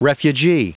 Prononciation du mot refugee en anglais (fichier audio)
Prononciation du mot : refugee